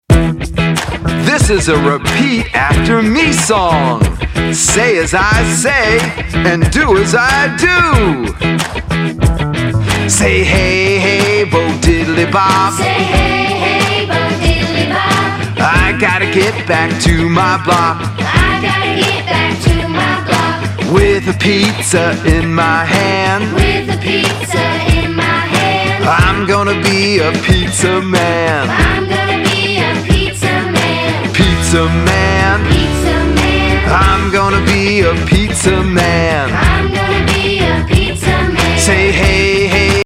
fun, upbeat, dance, exercise and creative movement songs